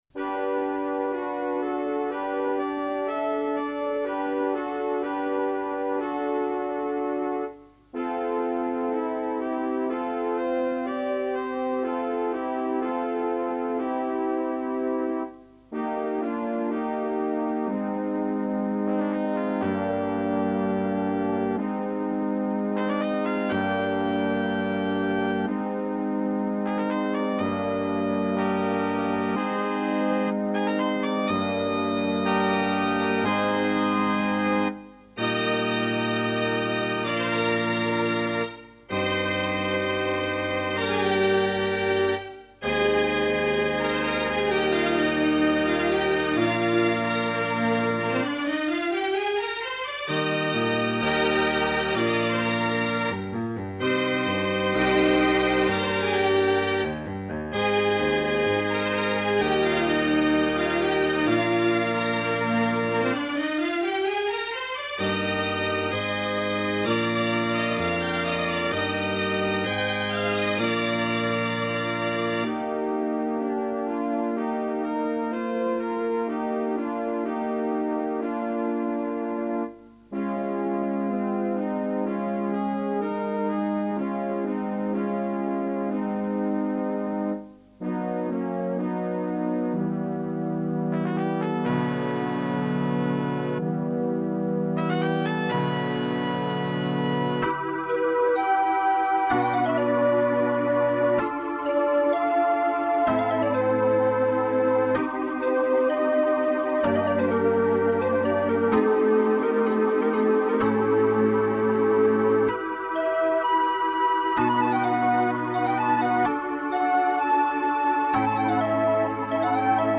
(classical style)